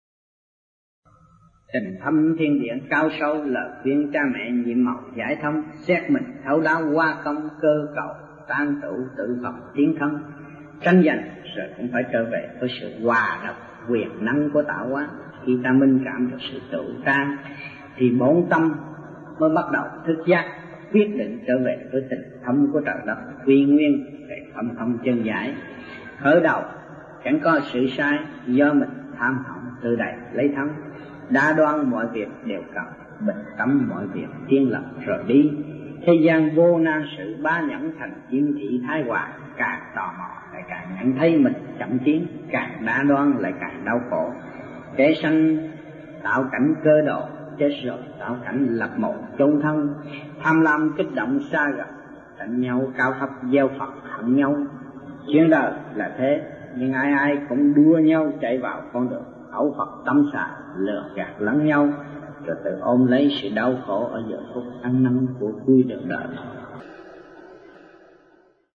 Địa danh : Sài Gòn, Việt Nam
Trong dịp : Sinh hoạt thiền đường